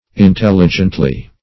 Intelligently \In*tel"li*gent*ly\, adv.